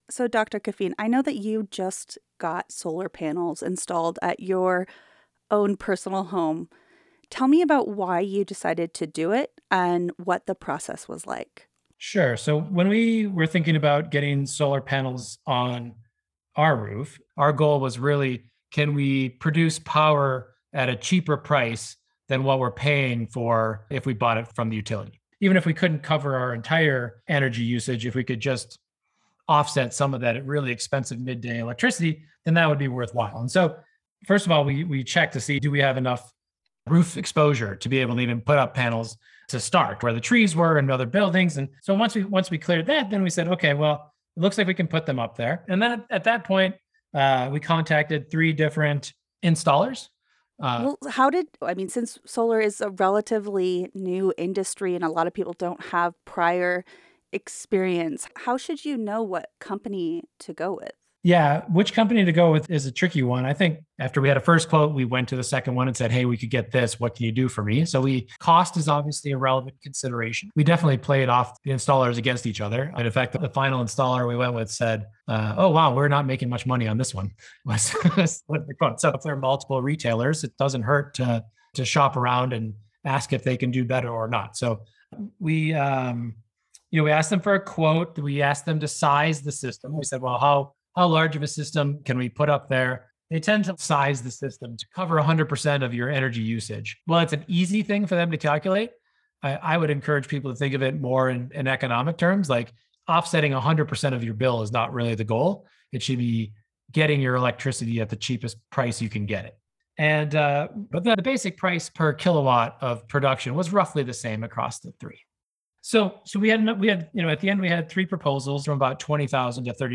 KGNU Broadcast Live On-Air